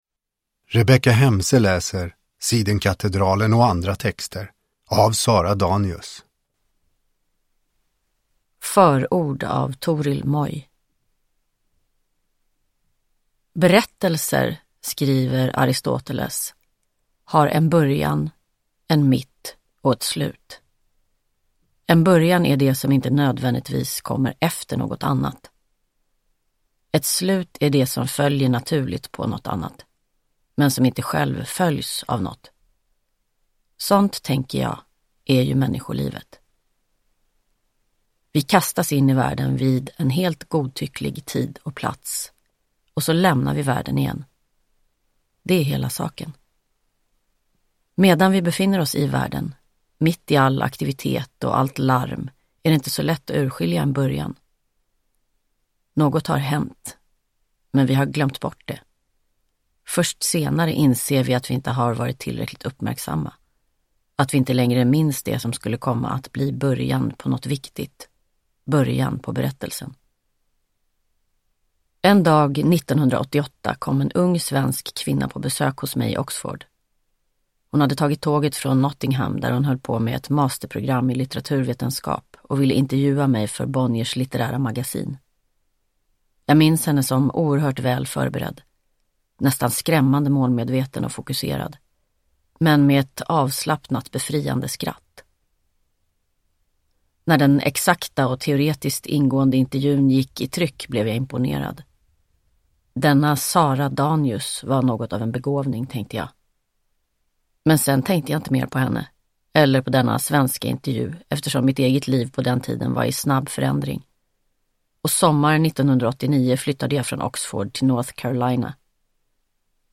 Uppläsare: Rebecka Hemse